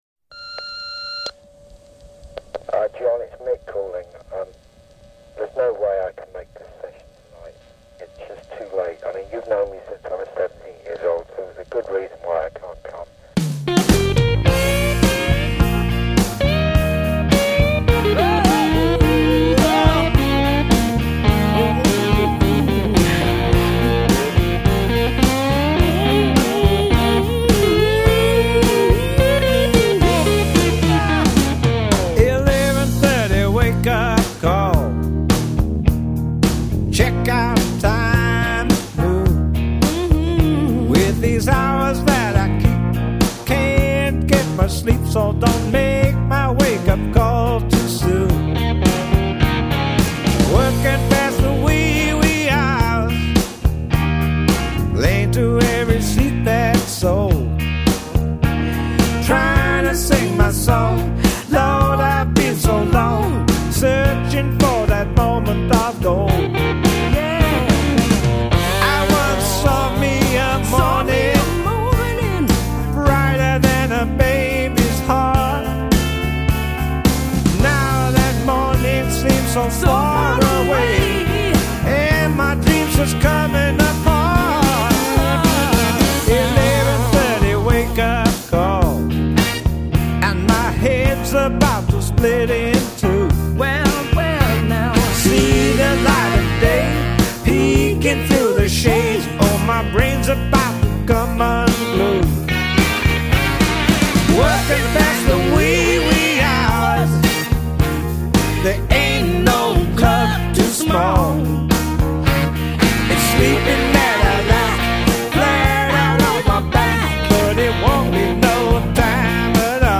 British blues
guitare rythmique
basse
batterie-.